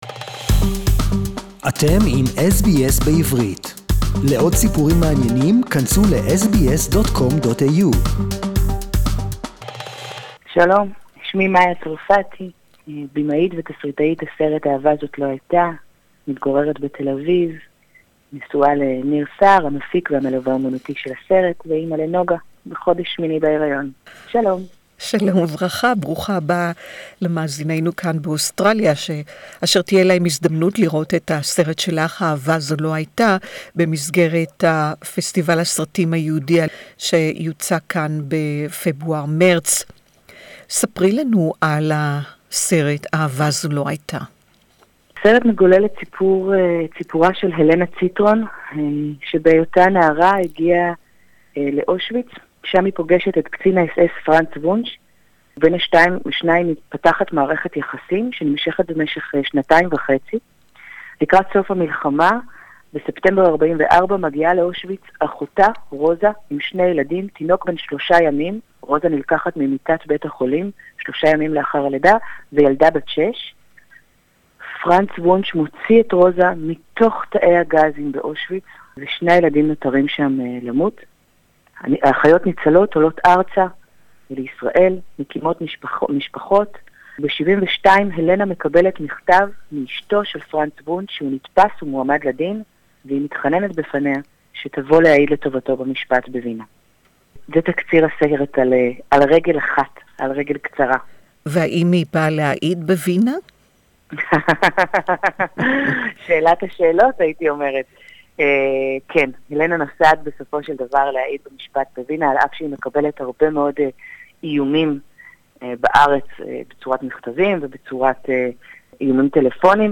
Hebrew interview not to be missed